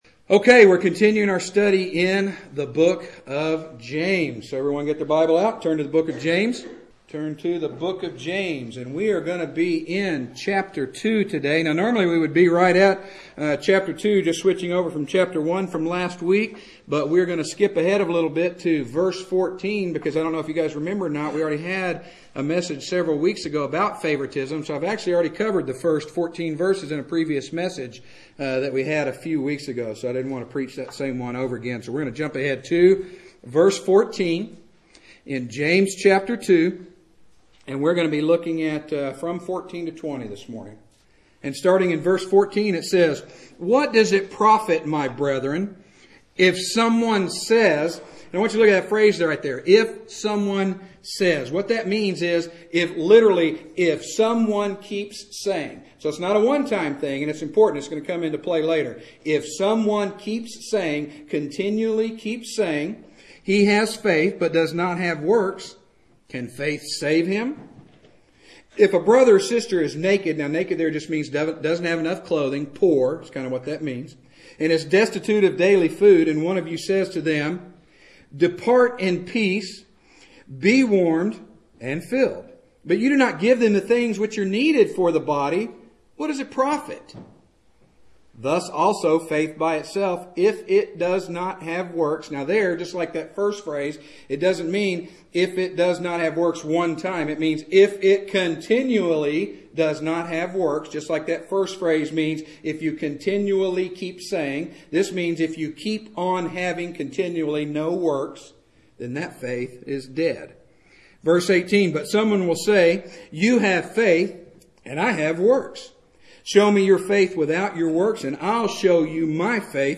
Preached at Straightway Bible Church on March 17th, 2013.